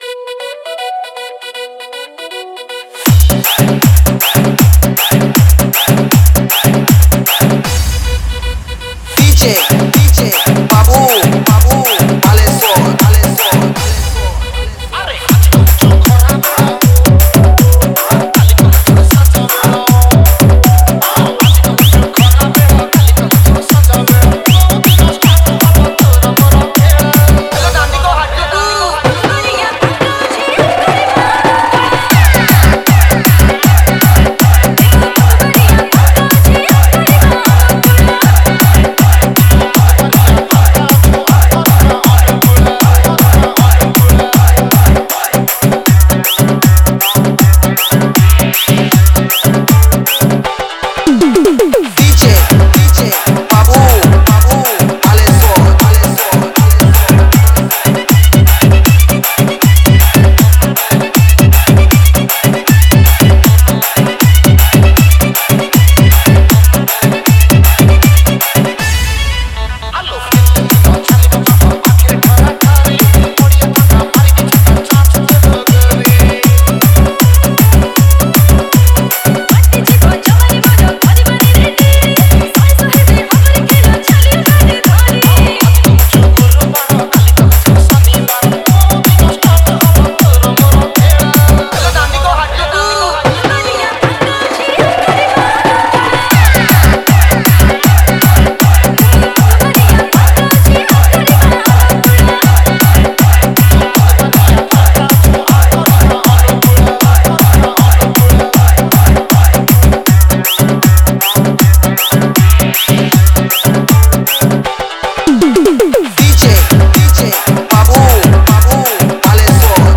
Category:  New Odia Dj Song 2021